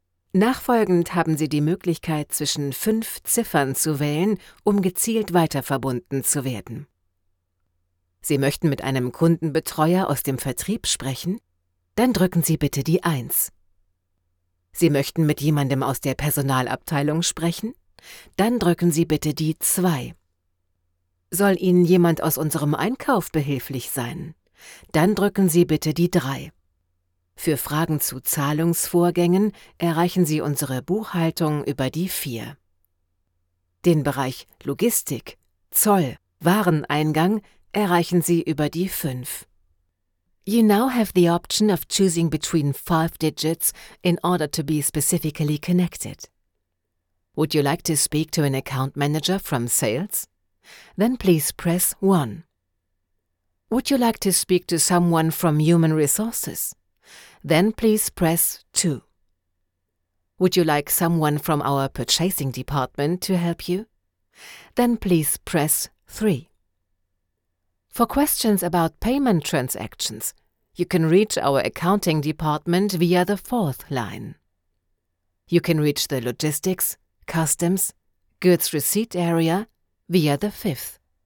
Profundo, Natural, Versátil, Cálida, Empresarial
Telefonía